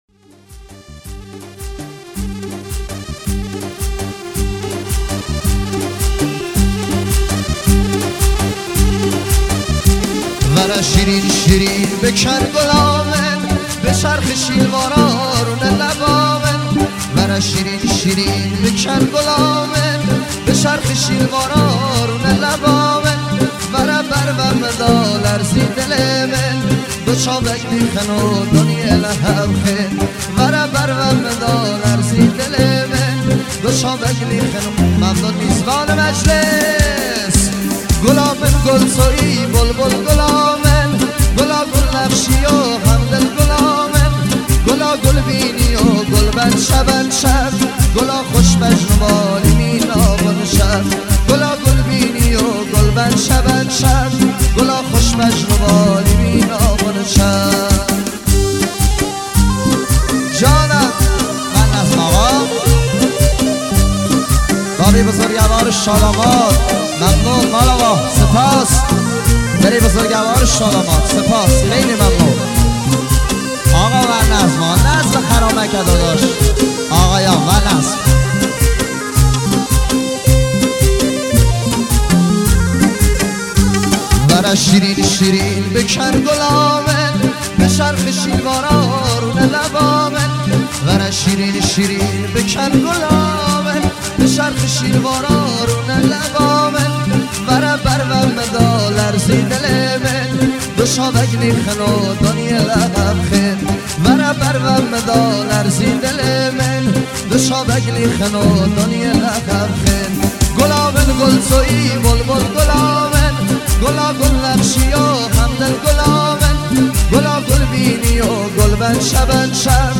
کرمانجی